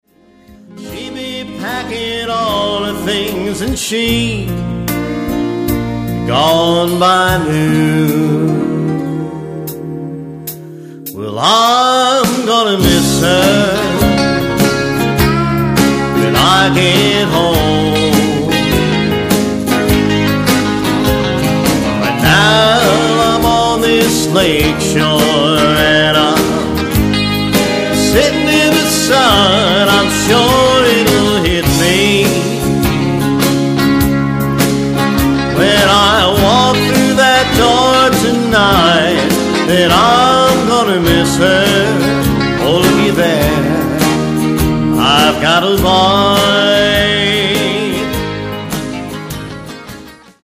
country song